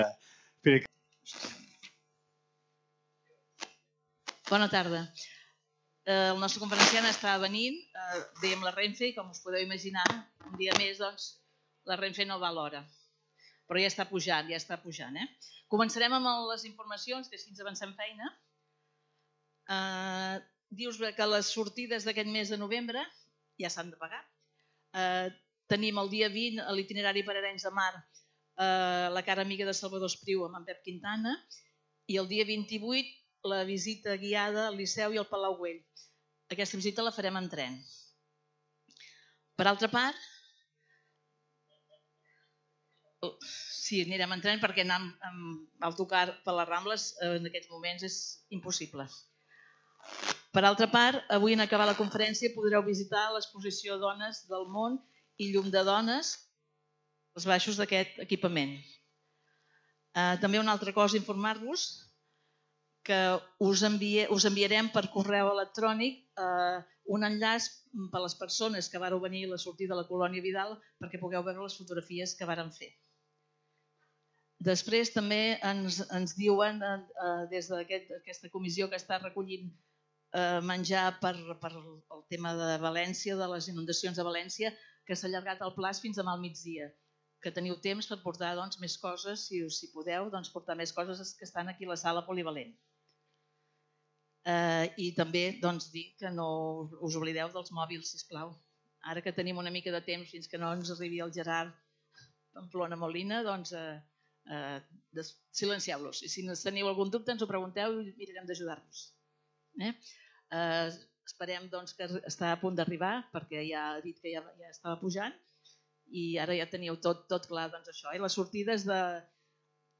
Lloc: Centre Cultural Calisay
Conferències